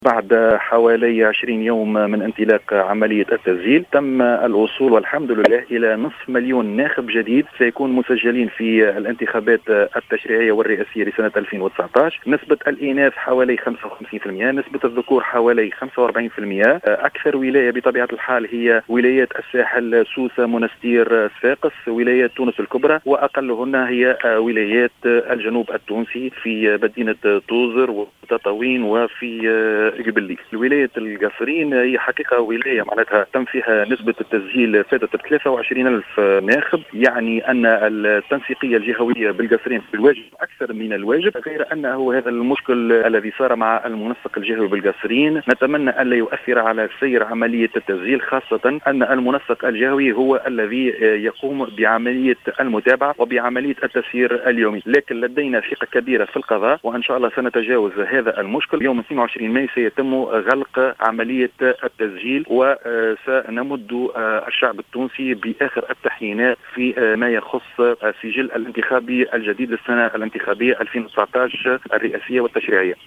وفي تصريح للجوهرة أف أم، أكد الجربوعي تسجيل أكبر نسب تسجيل في ولايات الساحل إلى جانب صفاقس وولايات تونس الكبرى، بينما تذيلت القائمة ولايات الجنوب التونسي وخاصة توزر وتطاوين وقبلي.